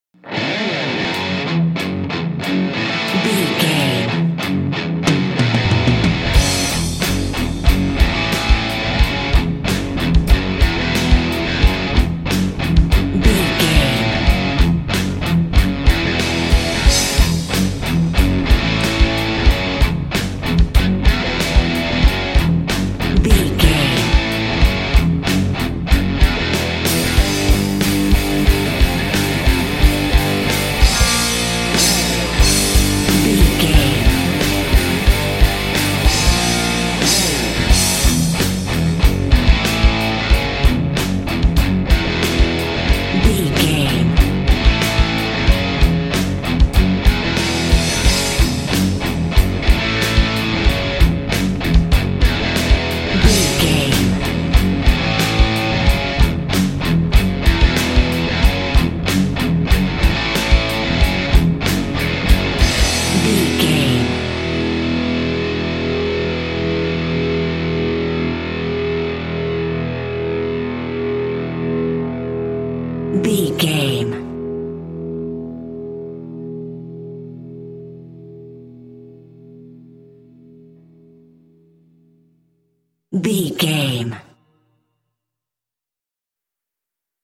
Ionian/Major
D
drums
electric guitar
bass guitar
hard rock
aggressive
energetic
intense
nu metal
alternative metal